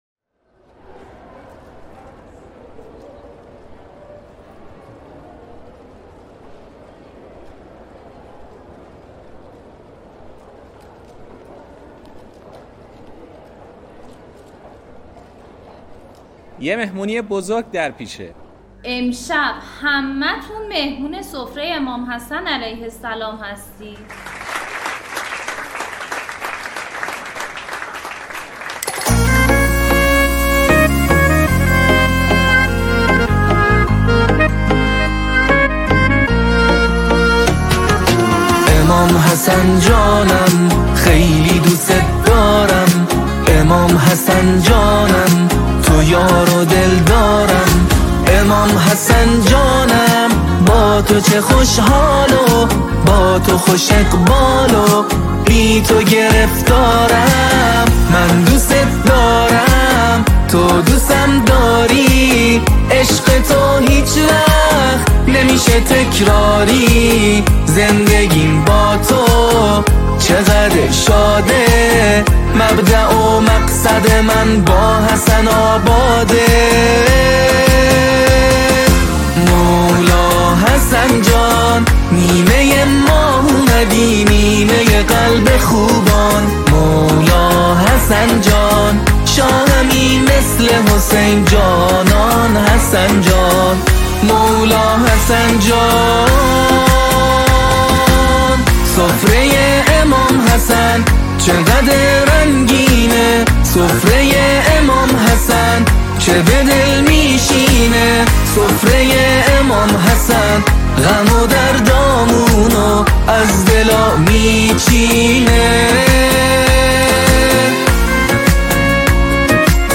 با صدای دلنشین
همخوای گروه‌های سرود